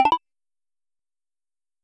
SFX_UI_Pause.mp3